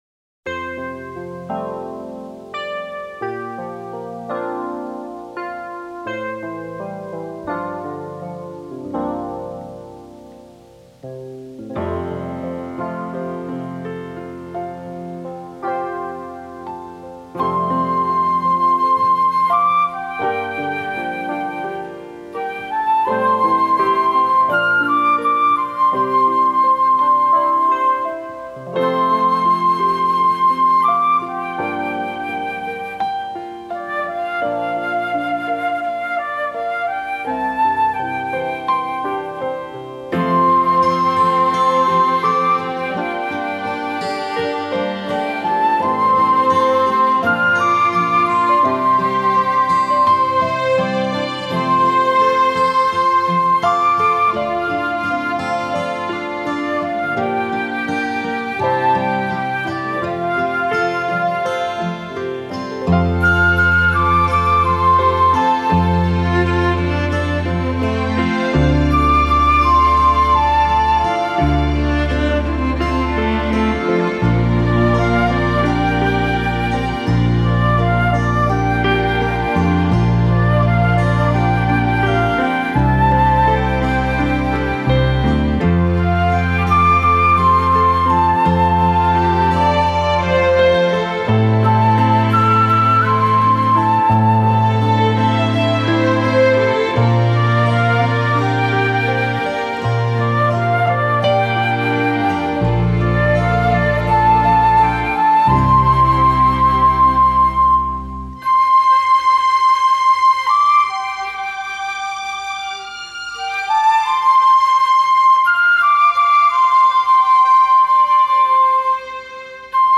Disk2-纯乐篇
分 類：原聲大碟(五)
類 別：[影視金曲]